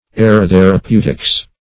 aerotherapeutics.mp3